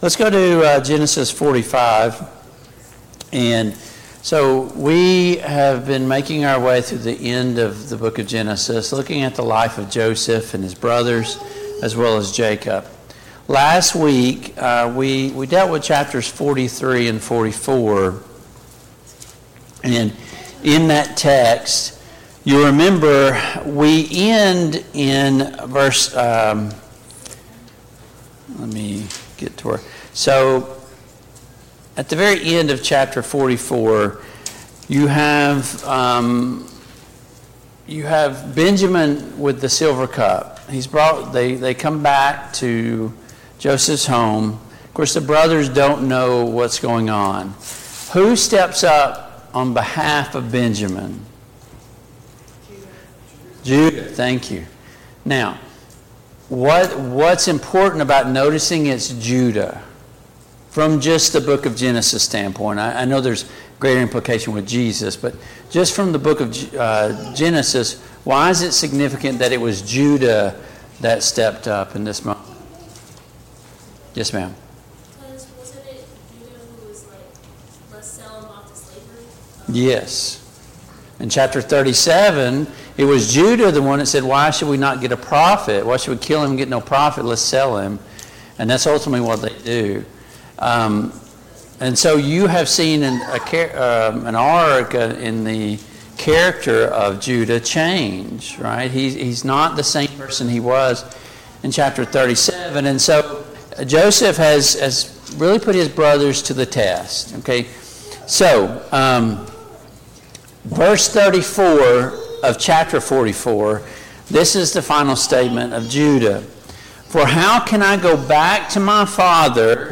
Genesis 44:1-5 Service Type: Family Bible Hour Topics: Joseph and his brothers « Did Jesus command us not to judge?